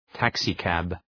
{‘tæksı,kæb}